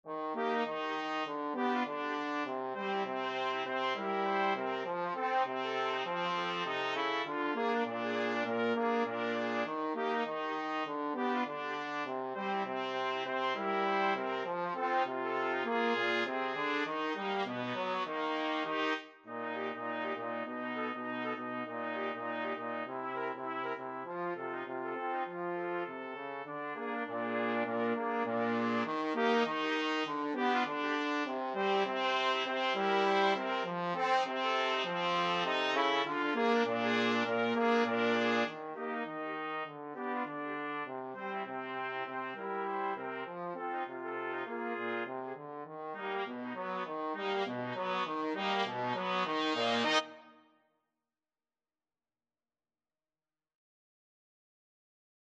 Trumpet 1Trumpet 2Trombone
Quick Swing = c. 100
Jazz (View more Jazz 2-Trumpets-Trombone Music)